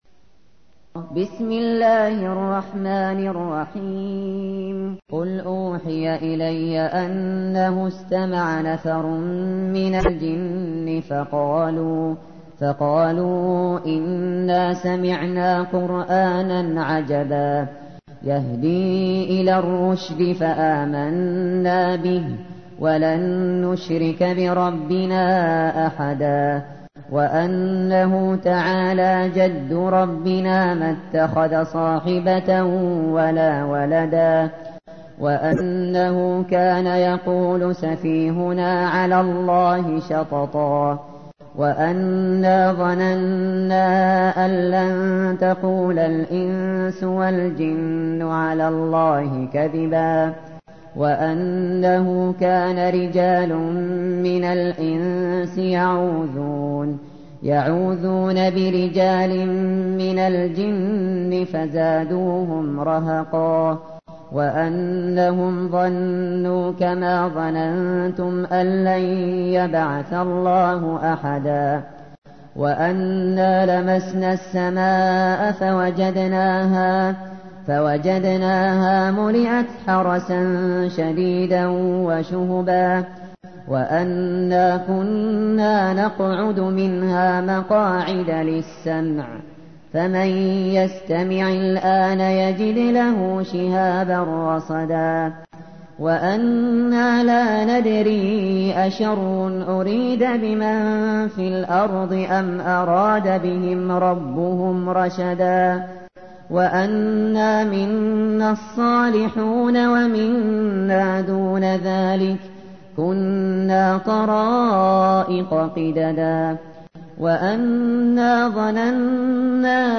تحميل : 72. سورة الجن / القارئ الشاطري / القرآن الكريم / موقع يا حسين